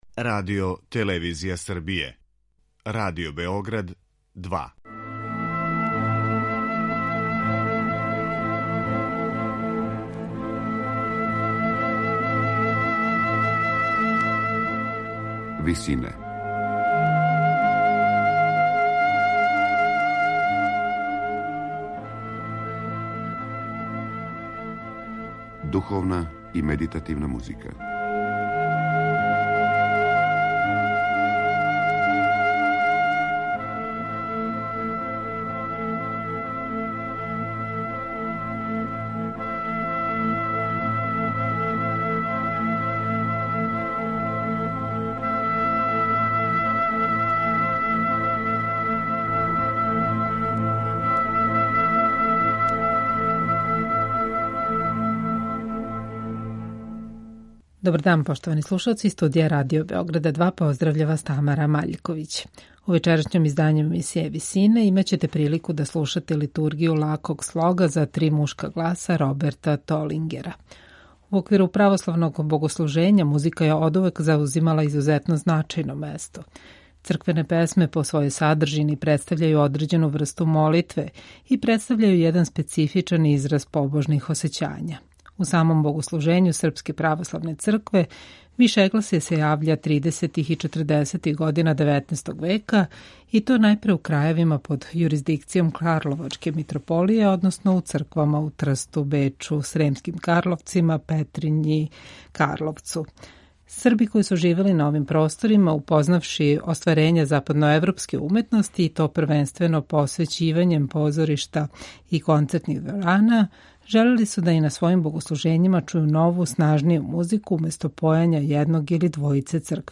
На крају програма, у ВИСИНАМА представљамо медитативне и духовне композиције аутора свих конфесија и епоха.
Међу њима је и Литургија лаког слога за три мушка гласа, коју слушамо у извођењу мушког хора Народног позоришта у Београду.